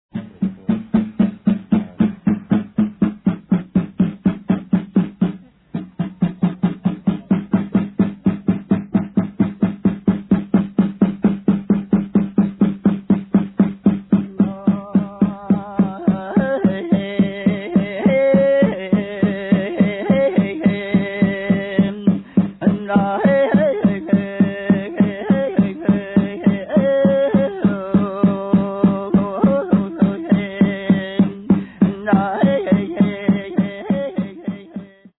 Rains Song/Chant & Drums - 1:11